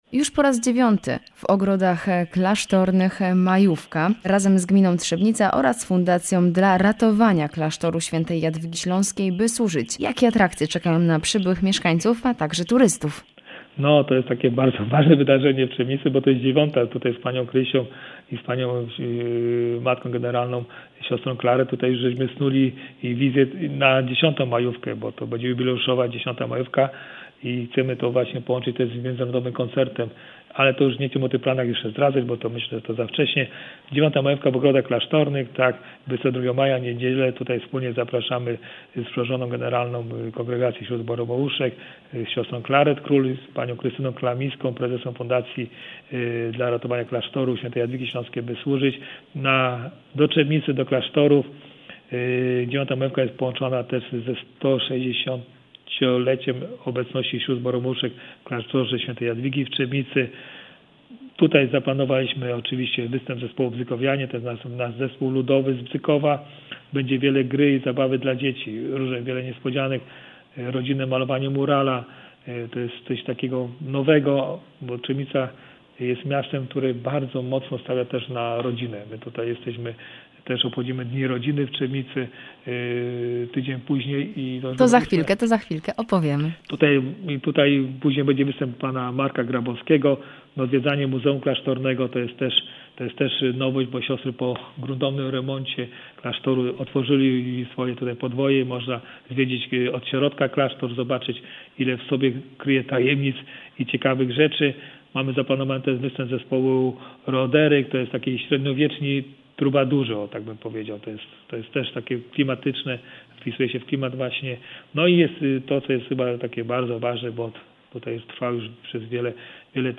03_rozmowa-z-burmistrzem-Trzebnica-1.mp3